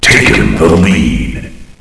takenlead.ogg